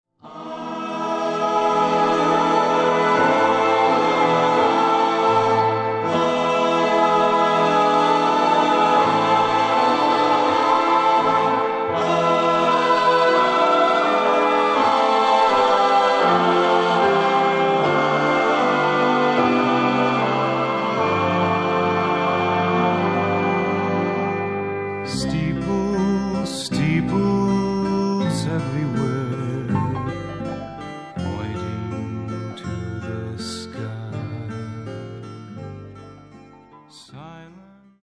女性一人を含むテキサス出身の4人組